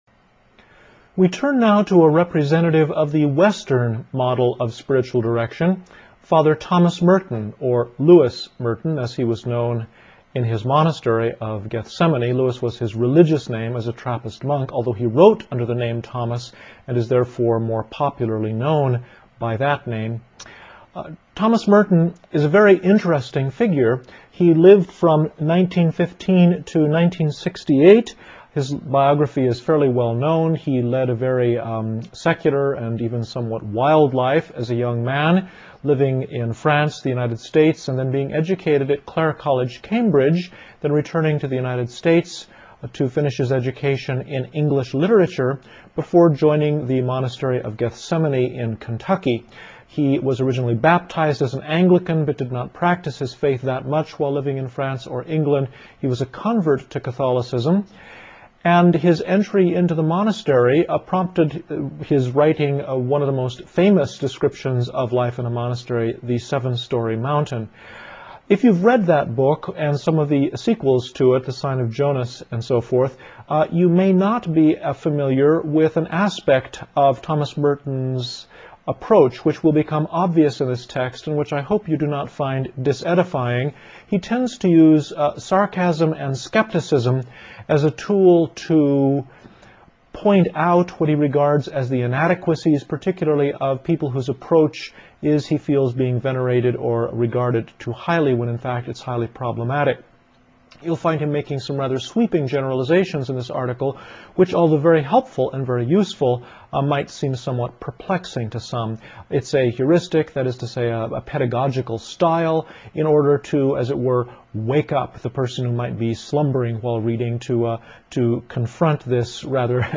THOMAS MERTON (West) Audio lect.-1 Audio lect.-2